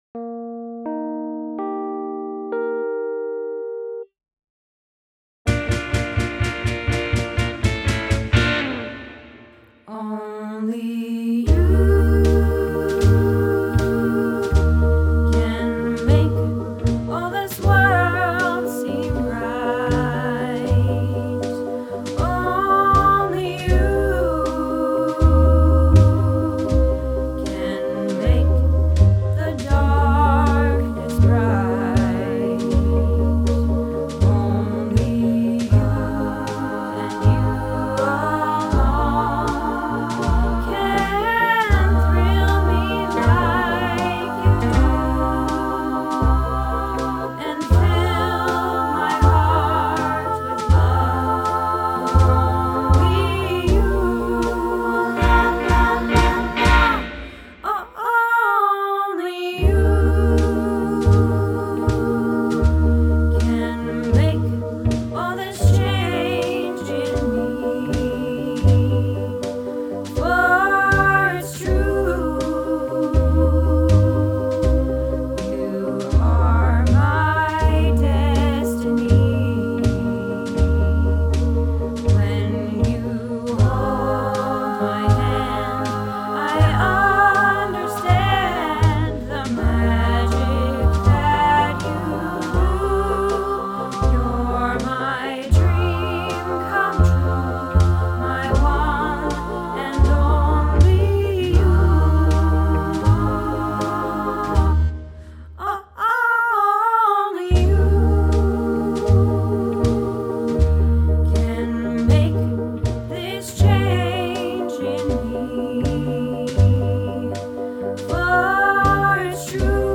Only You - Practice